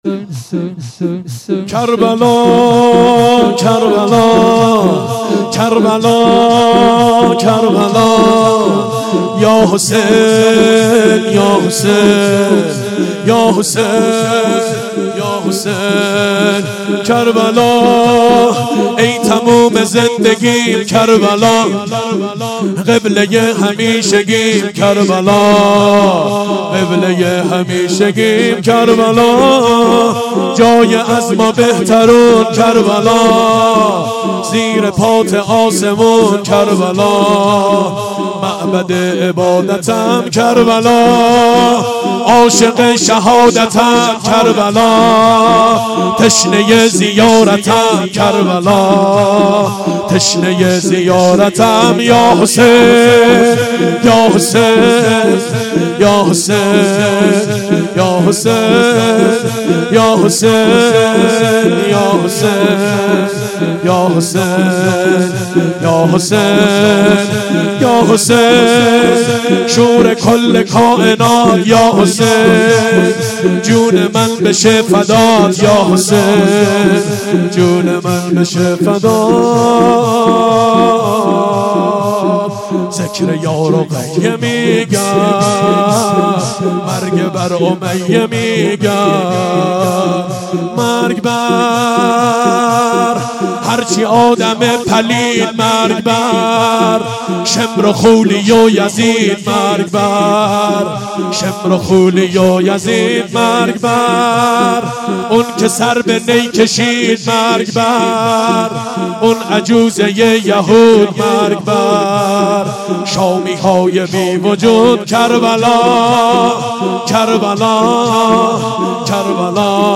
شور4